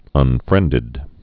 (ŭn-frĕndĭd)